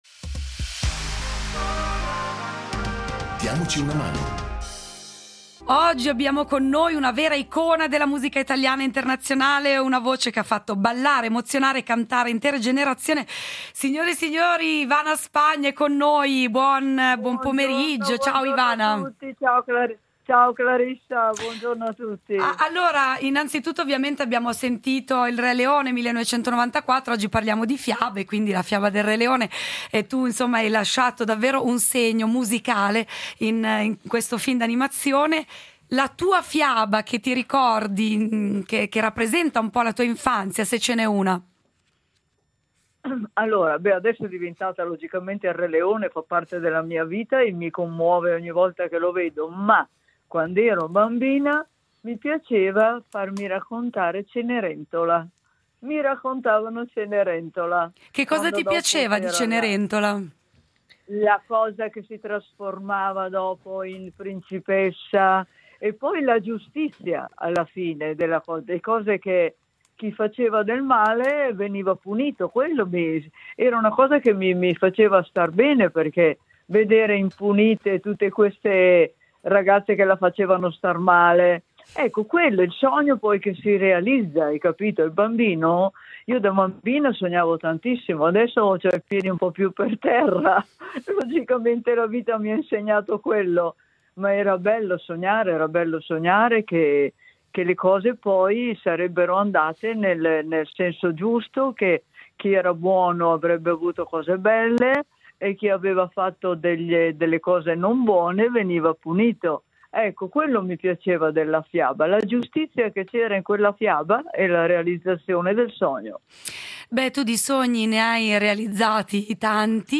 Incontro con la cantautrice italiana Ivana Spagna